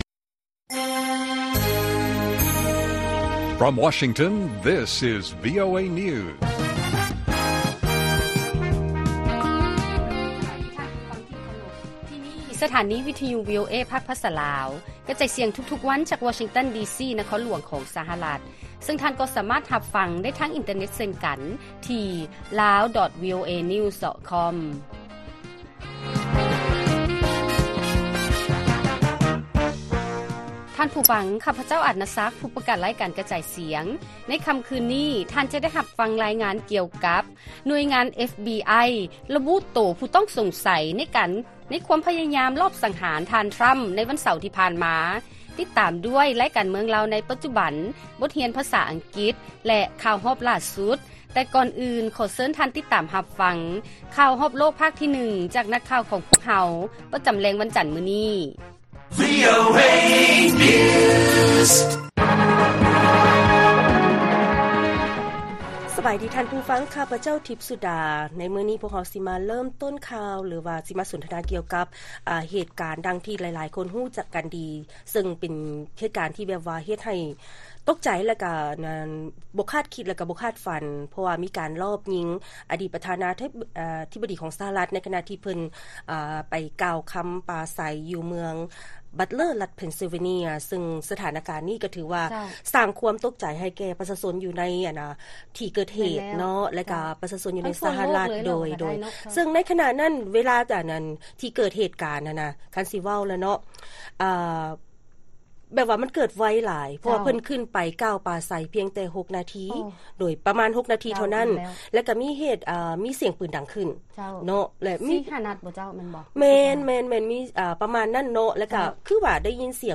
ລາຍການກະຈາຍສຽງຂອງວີໂອເອ ລາວ: ໜ່ວຍງານ FBI ລະບຸໂຕຜູ້ຕ້ອງສົງໃສ ໃນຄວາມພະຍາຍາມລອບສັງຫານ ທ່ານ ທຣຳ ໃນວັນເສົາທີ່ຜ່ານມາ